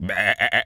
Animal_Impersonations
goat_baa_calm_08.wav